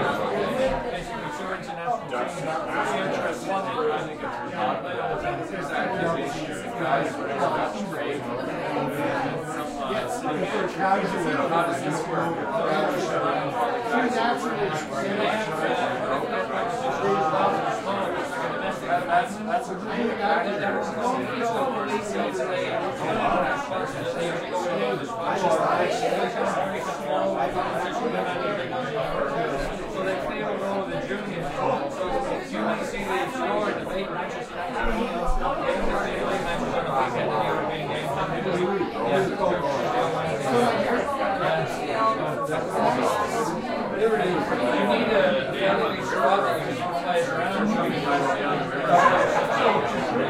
teenageCrowdInteriorLoop.ogg